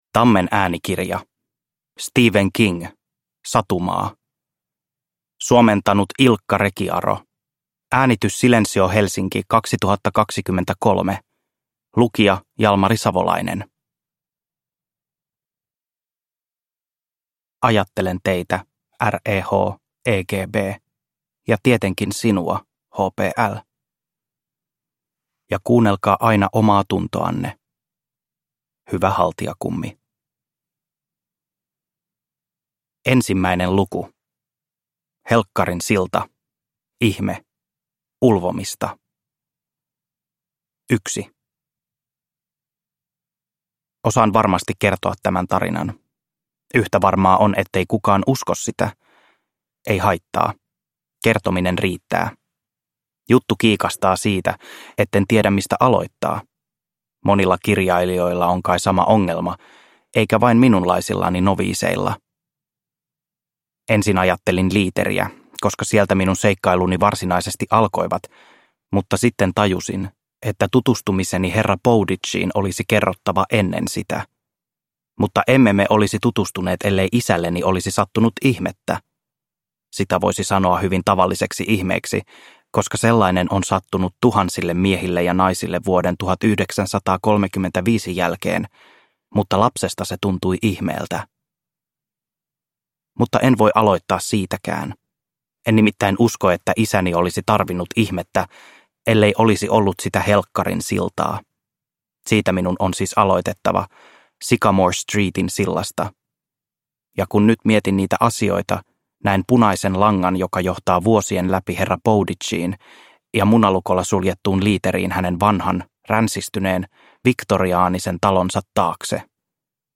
Satumaa – Ljudbok – Laddas ner